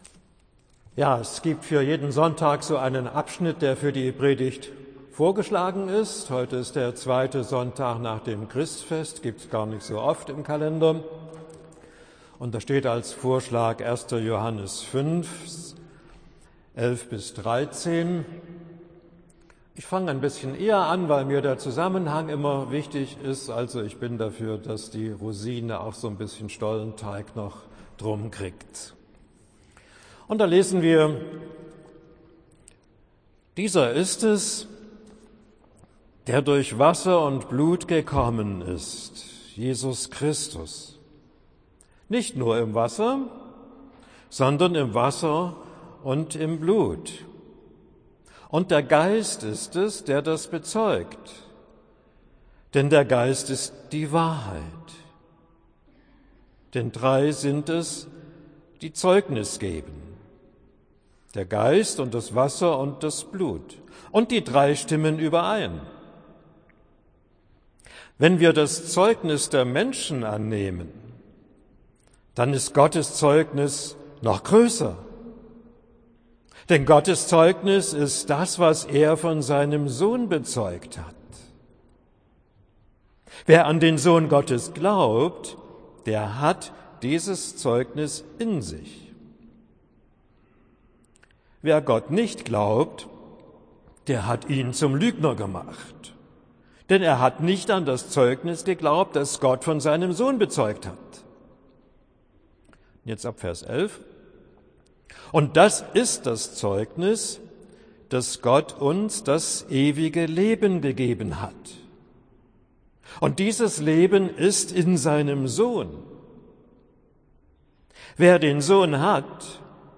Predigt für den 2. Sonntag nach dem Christfest